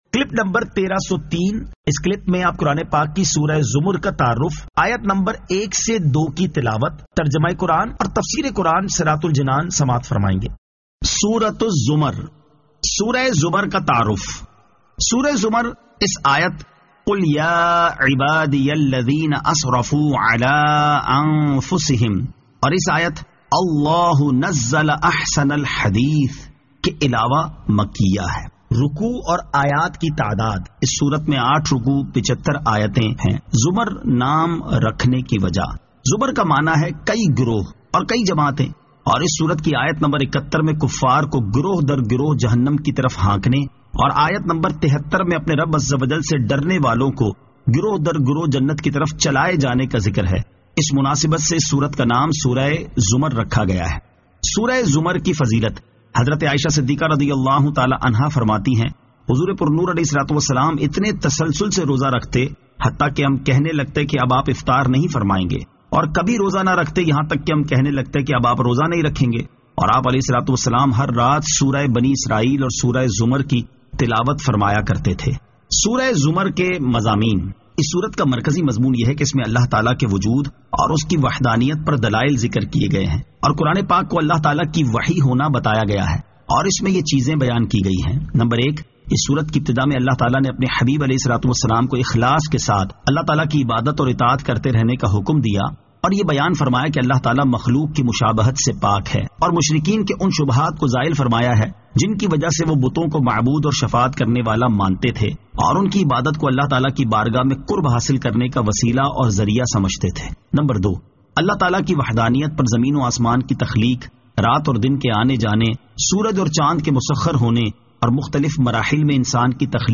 Surah Az-Zamar 01 To 02 Tilawat , Tarjama , Tafseer